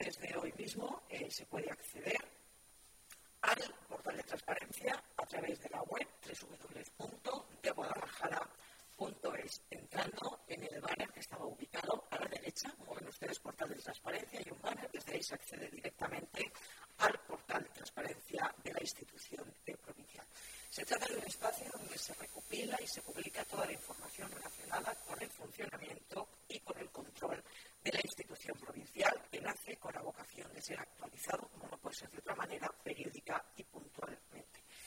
La presidenta de la Diputación, Ana Guarinos, ha sido la encargada de darlo a conocer en rueda de prensa acompañada por el vicepresidente Lorenzo Robisco y el diputado delegado de Nuevas Tecnologías, Alberto Domínguez.